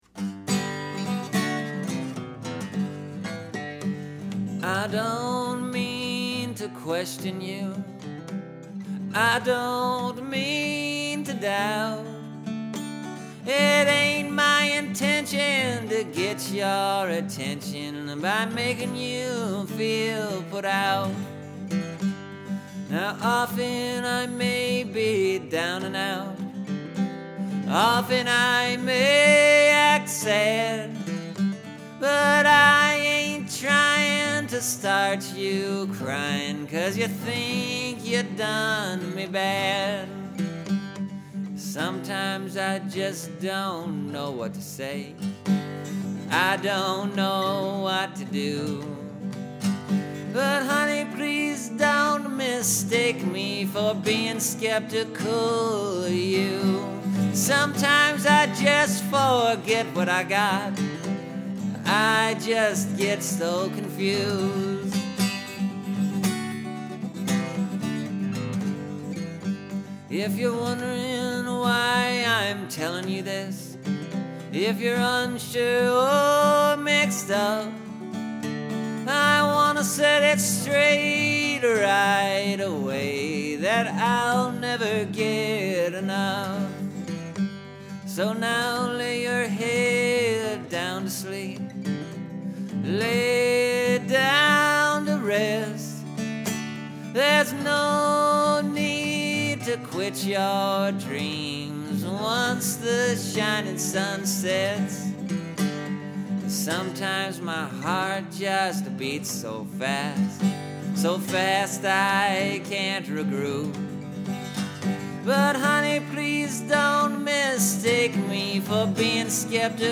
This new version is kinda more like a folk song.